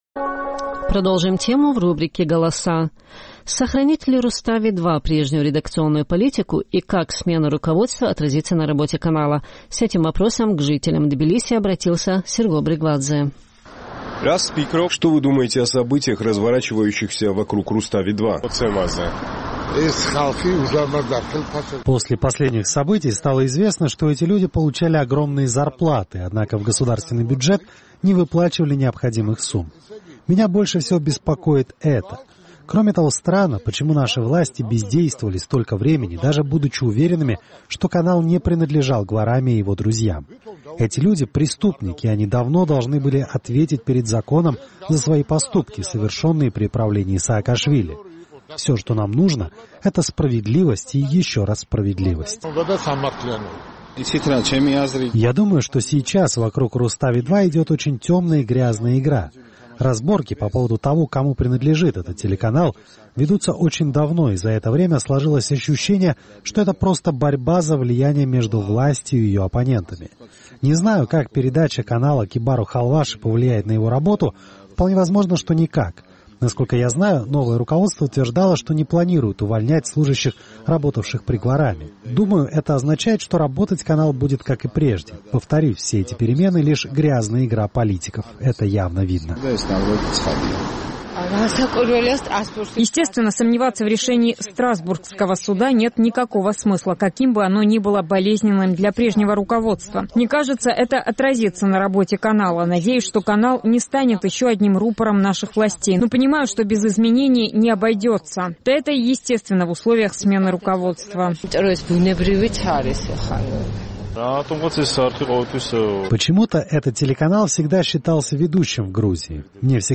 Сохранит ли «Рустави 2» прежнюю редакционную политику и как смена руководства отразится на работе канала? Наш тбилисский корреспондент поинтересовался мнением жителей грузинской столицы по этому поводу.